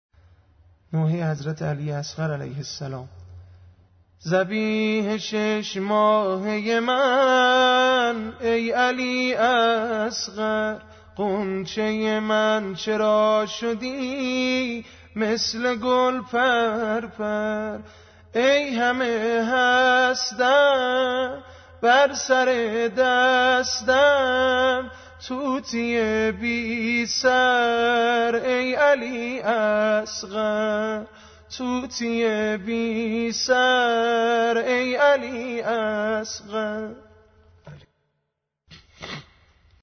عنوان : سبک حضرت علی اصغر علیه السلام